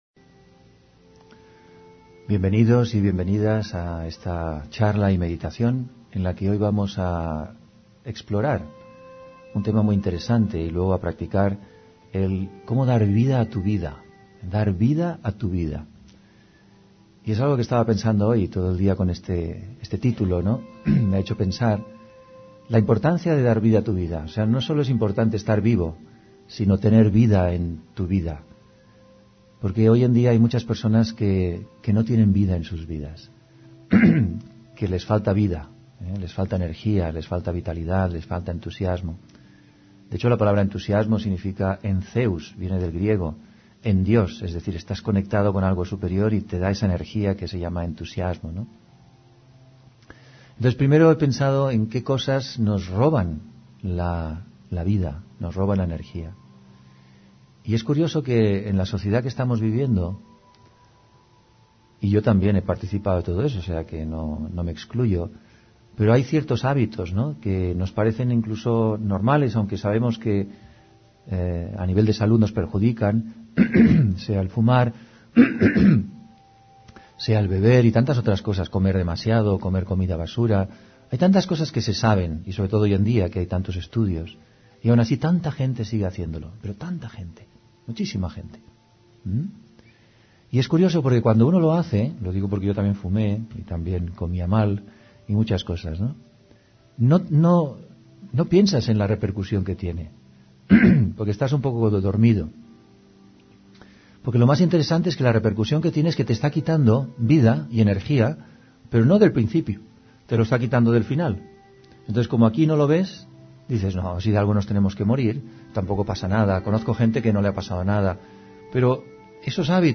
Meditación y conferencia: Da vida a tu vida (28 Abril 2023)